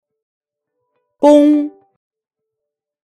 Audio file of the word "Gong"
Gong_audio_word.mp3